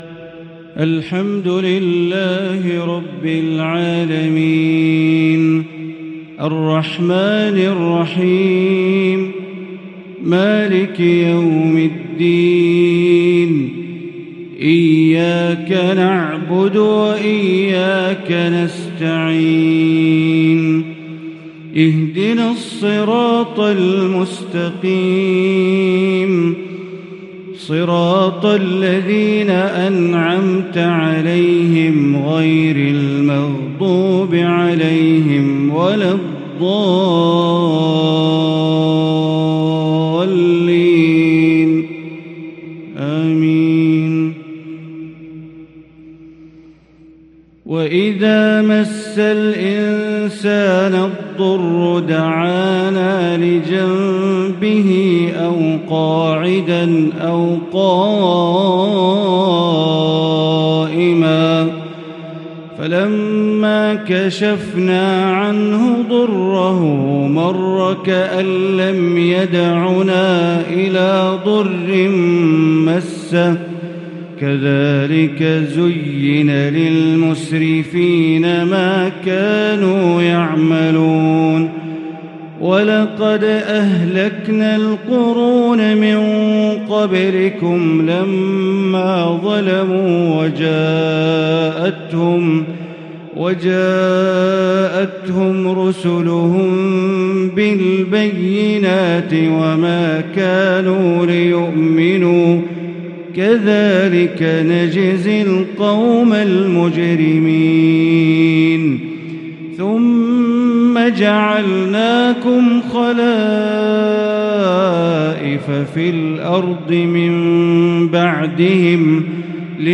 عشاء السبت 7-2-1444 هـ من سورة يونس | Isha Prayer from Surat Yunus 3-9-2022 > 1444 🕋 > الفروض - تلاوات الحرمين